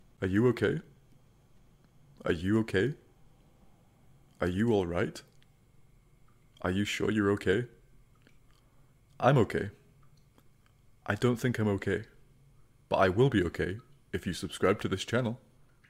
Are you ok? sound effect
Thể loại: Âm thanh meme Việt Nam
are-you-ok-sound-effect-www_tiengdong_com.mp3